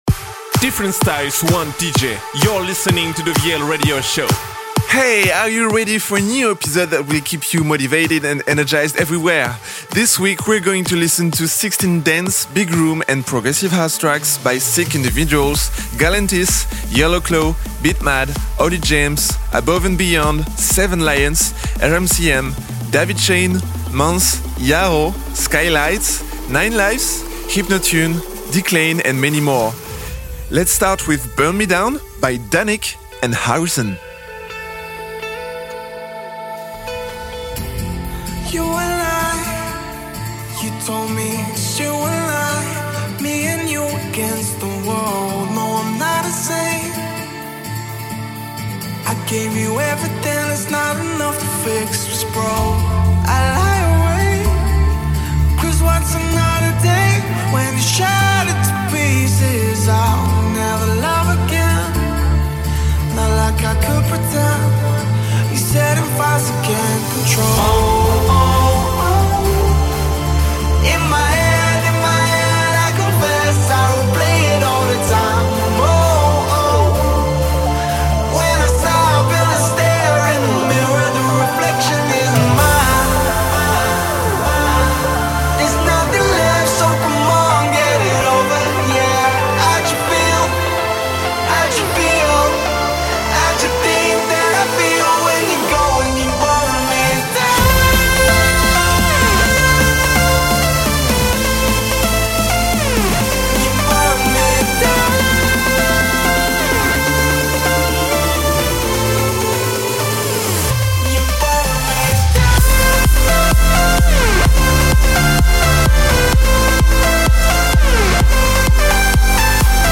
Big room, dance & progressive house DJ mix.